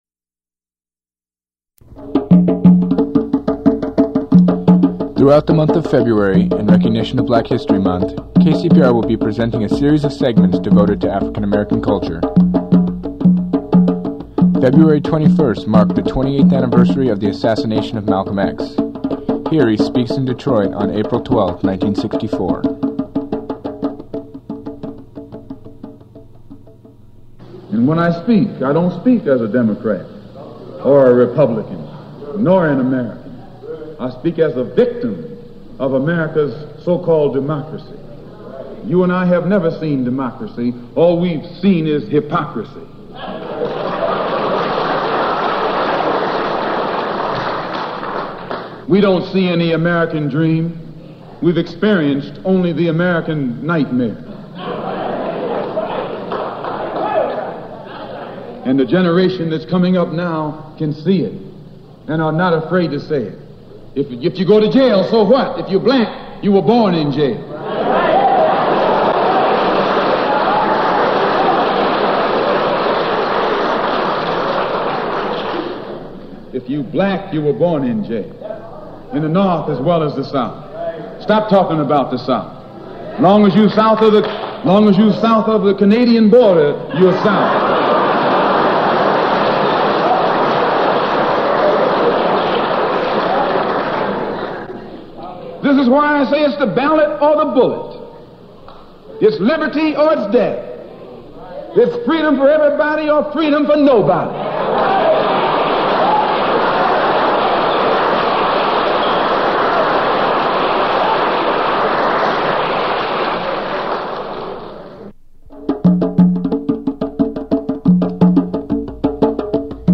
Black History Month: Malcolm X, Speaks 4/12/1964
Form of original Audiocassette